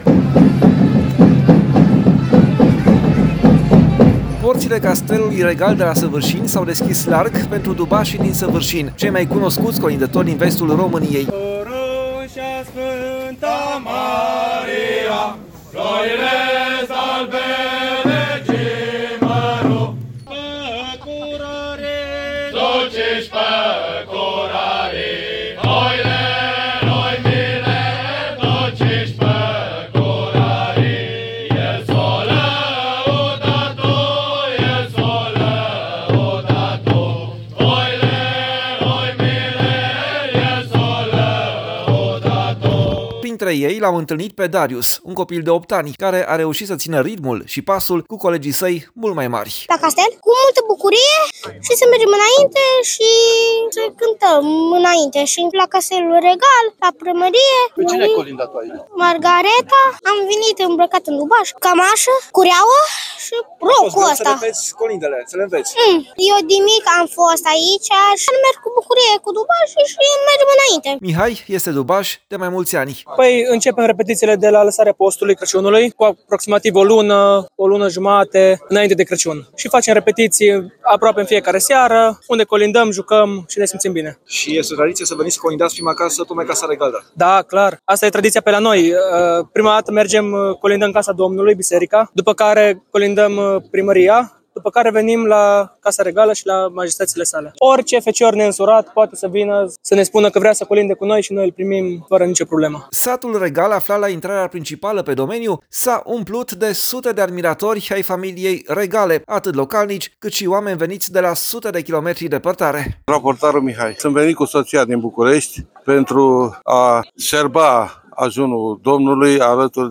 Dubașii din Săvârșin au colindat și anul acesta Familia Regală
A devenit deja o traditie ca, în Ajun, Majestățile lor sa fie colindate de dubasi din Săvârșin, cei mai cunoscuți colindători din vestul României.